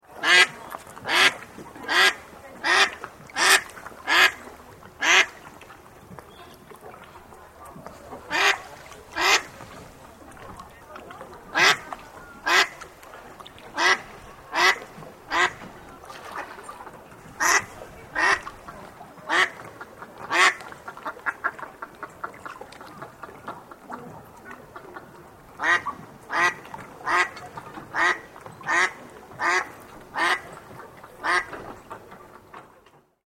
Duck-sound-effect.mp3